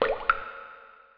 sewerdrop1.wav